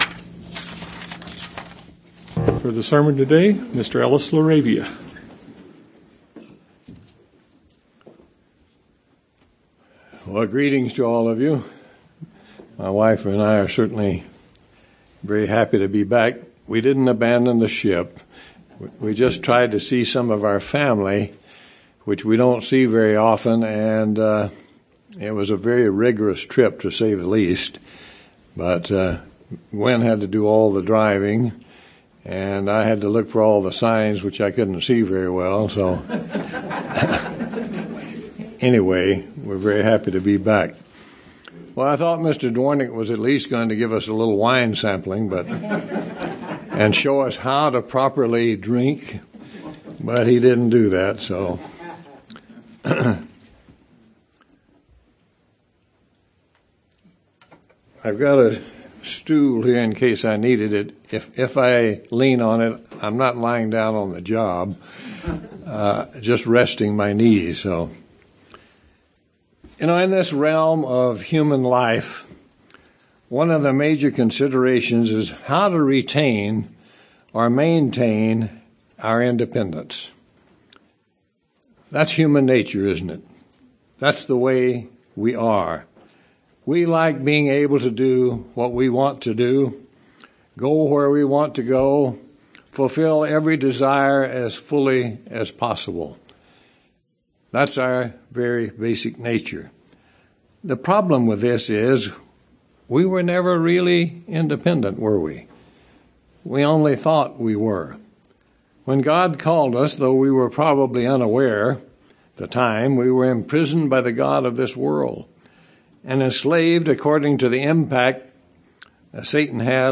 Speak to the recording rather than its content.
Given in Tucson, AZ El Paso, TX